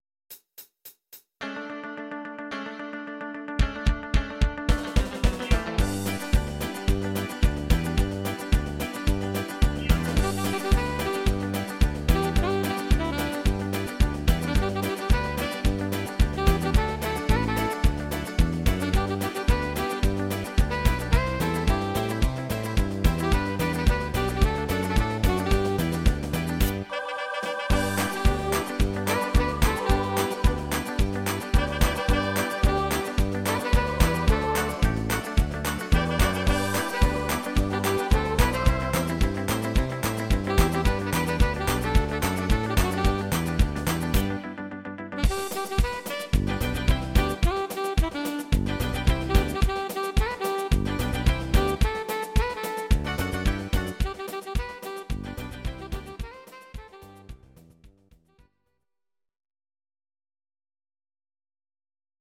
Audio Recordings based on Midi-files
Pop, Oldies, 1960s